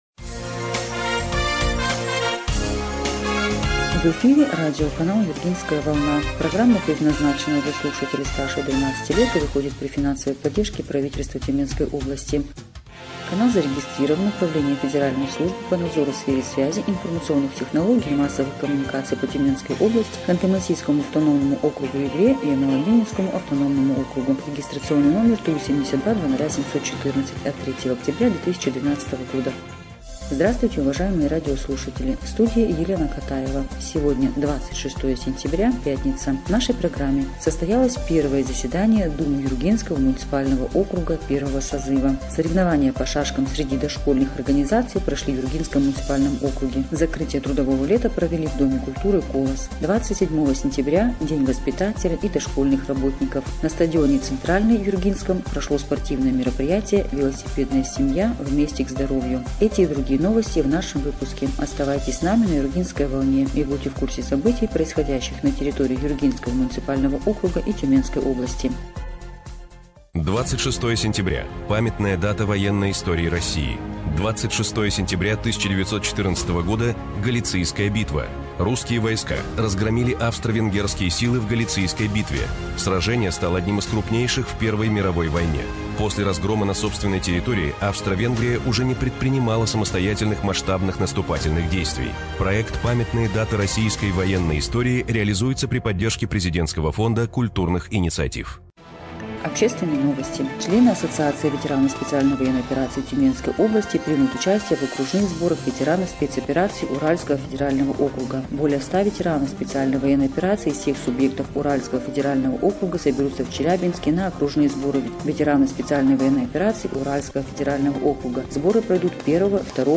Эфир радиопрограммы "Юргинская волна" от 26 сентября 2025 года